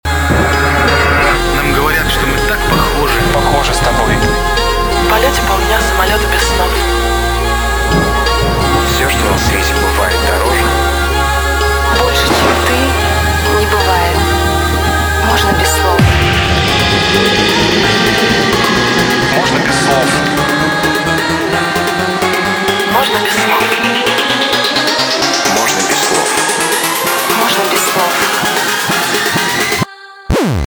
Жанр: Танцевальная